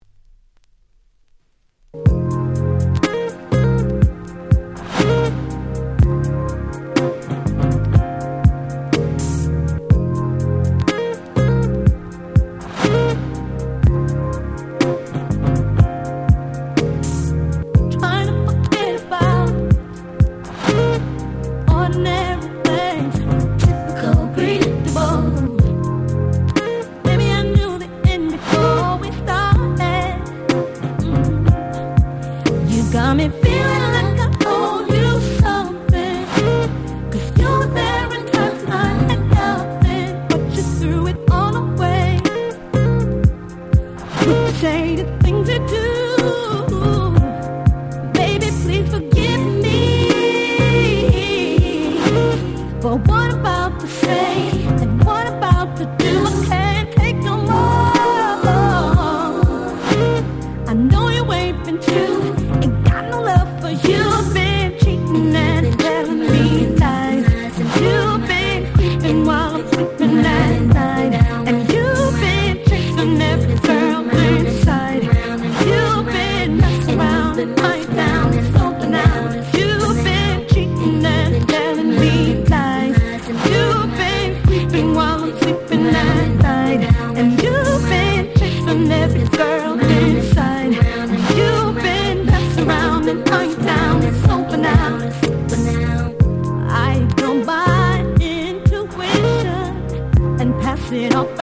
当時HotだったR&B4曲をこの盤オンリーのNice Remixに！！